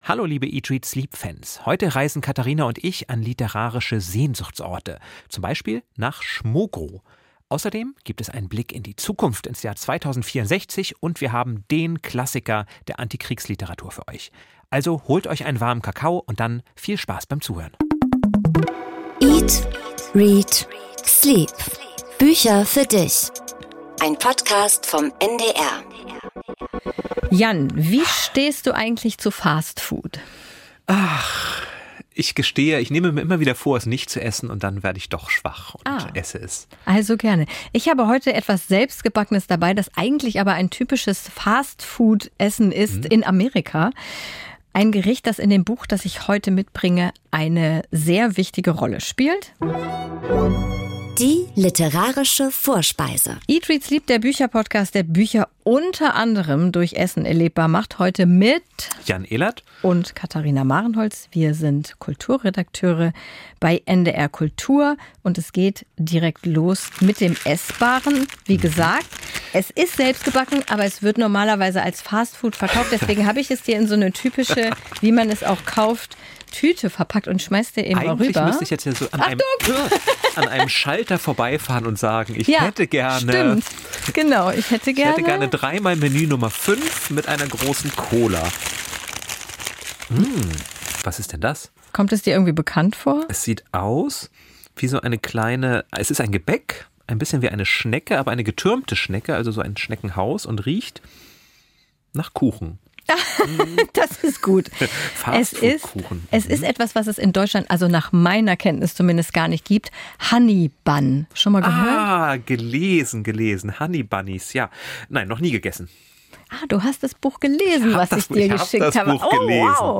Comfort-Food im Podcast-Studio: (Fast noch) warme Honey Bunny Buns sorgen für gute Stimmung in kalten Herbsttagen.